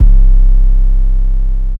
Going Krazy 808.wav